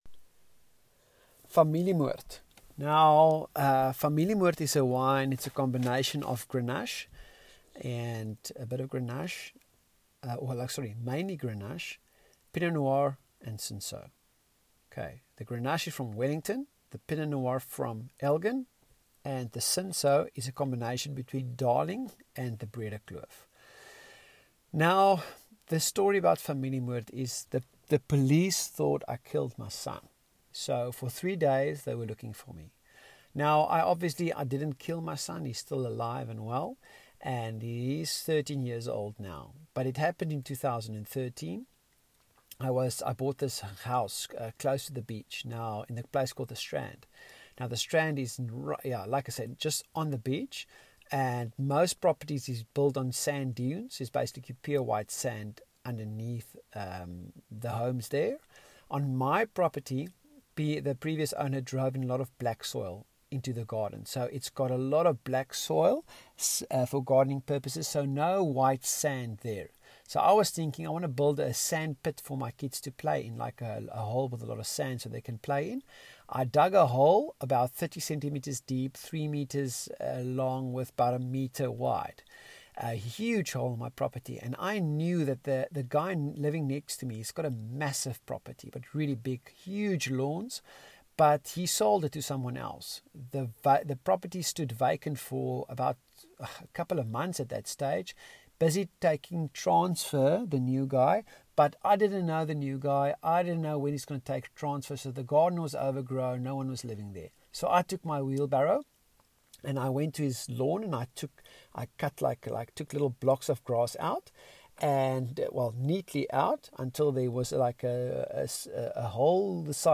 Voice Recording: